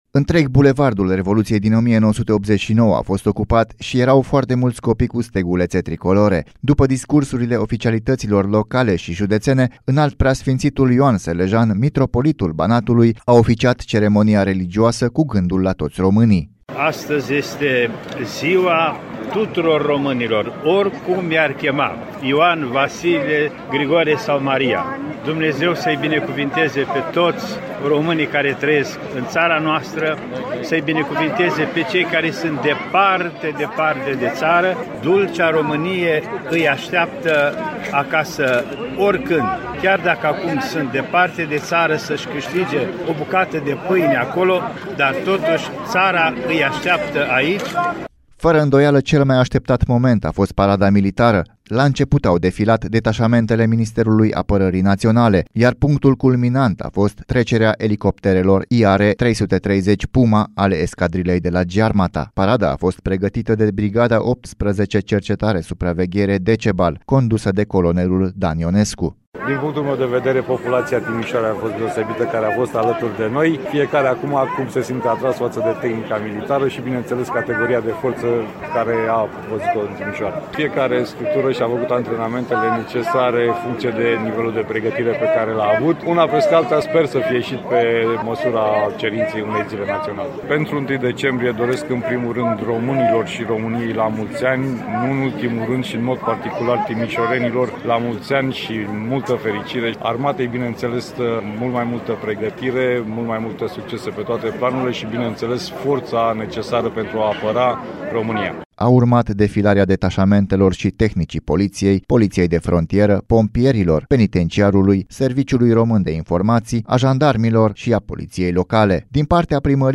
Timişorenii au participat în număr mare la evenimentele organizate de Ziua Naţională a României, în faţa Palatului Administrativ.